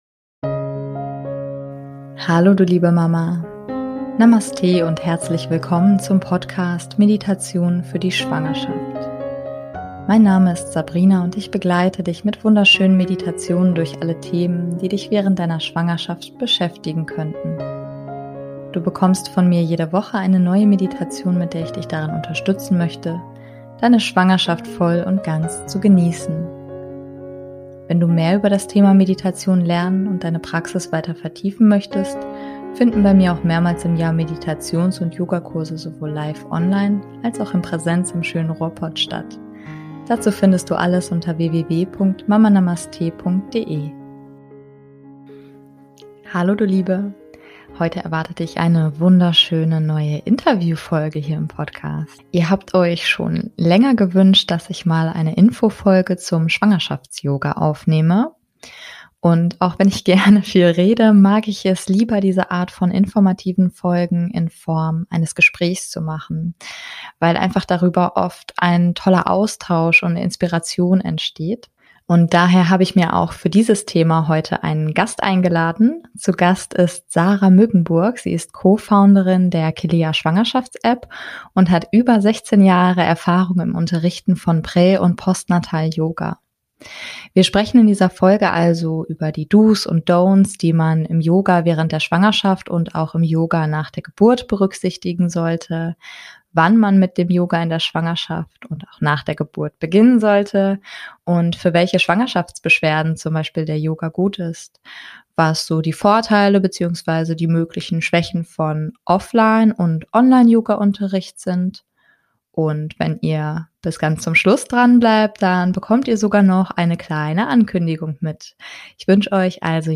Ihr habt euch schon länger gewünscht, dass ich mal eine Infofolge zum Schwangerschaftsyoga aufnehmen.